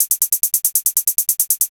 HIHAT019_TEKNO_140_X_SC2.wav